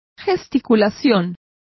Complete with pronunciation of the translation of gesticulation.